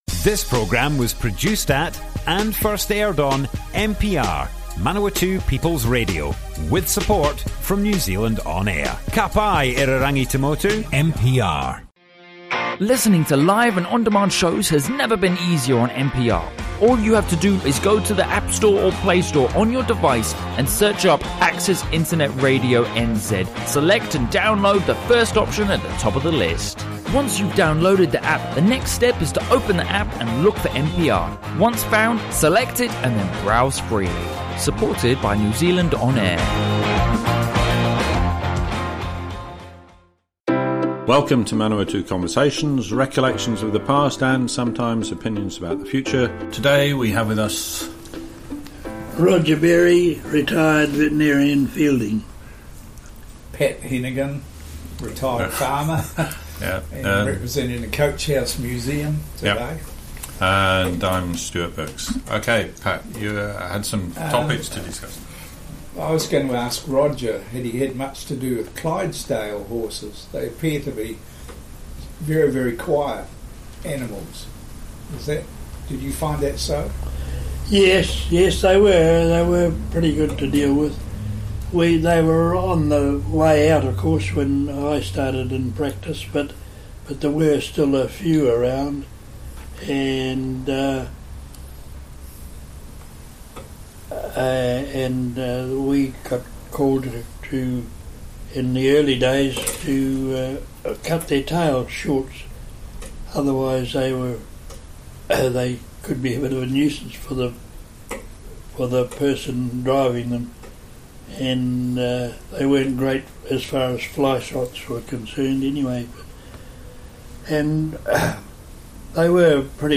Manawatu Conversations More Info → Description Broadcast on Manawatu People's Radio 5th March 2019.
oral history